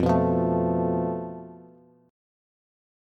FMb5 Chord
Listen to FMb5 strummed